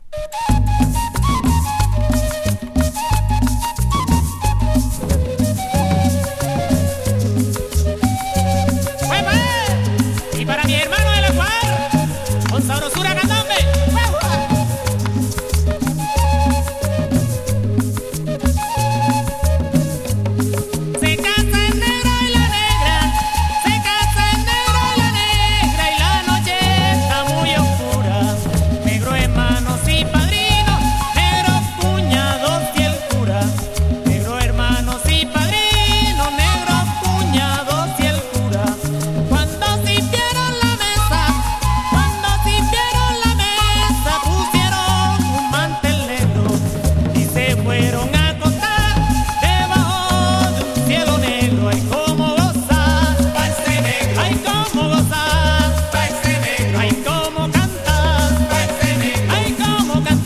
コロムビアのフォークロア・グループ。